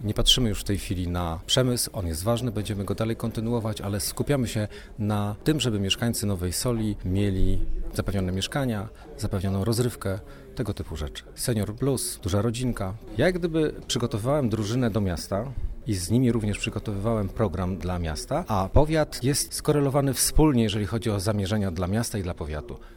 Konwencja powiatowa PiS w Nowej Soli
konwencja-pis-nowa-sol-1-13-10.mp3